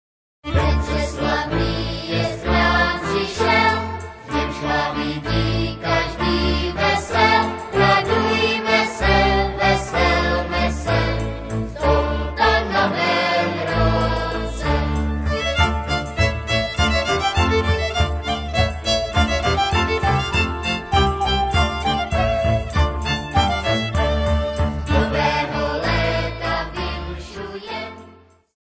lidová